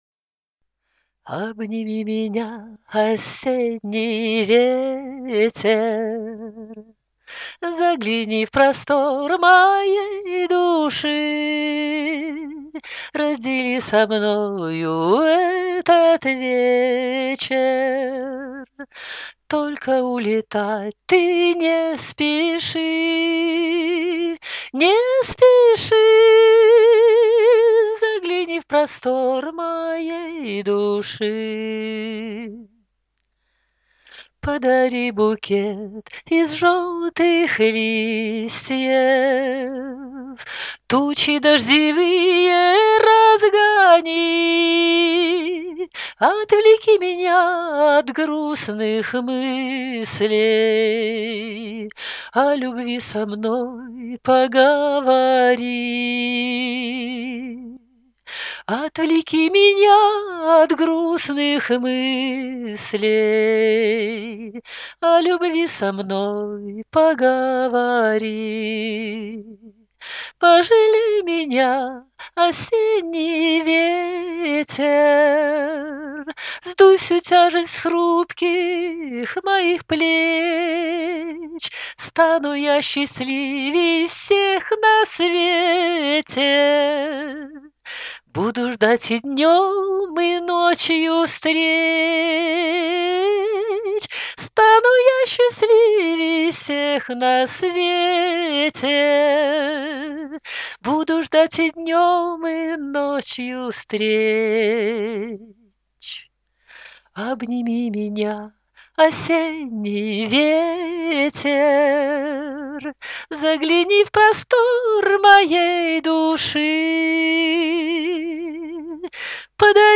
Только улетать ты- не спеши (Романс)
Рубрика: Поезія, Авторська пісня